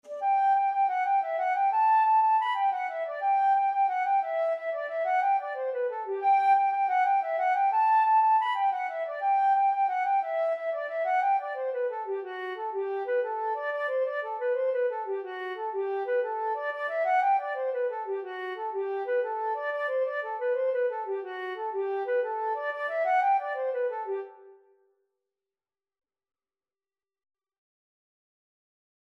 9/8 (View more 9/8 Music)
G major (Sounding Pitch) (View more G major Music for Flute )
Flute  (View more Easy Flute Music)
Traditional (View more Traditional Flute Music)